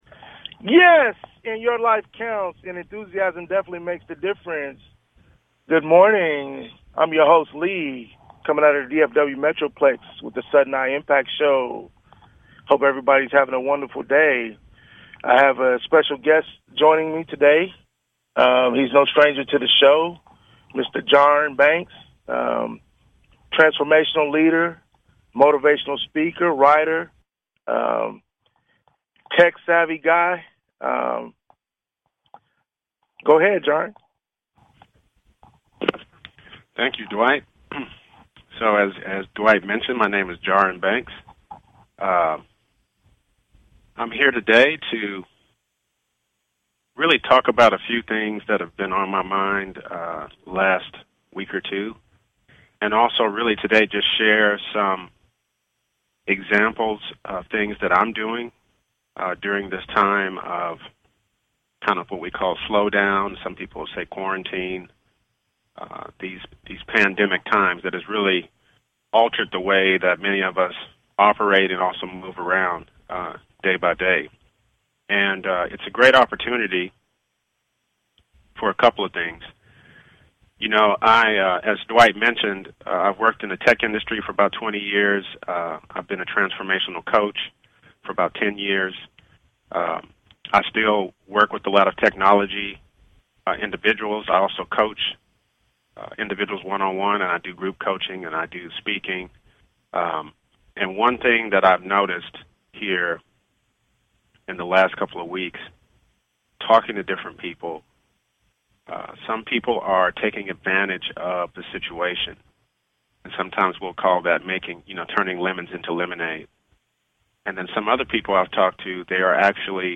Talk Show Episode
Sudden I Impact (sii) is a talk show about discovery, helping people raise their awareness and identify their gifts, finding things in life they enjoy doing, finding their uniqueness, and potentially turning their passions into businesses that thrive, and most of all, living life by plan and design to earn a living doing what they love (the essential thing).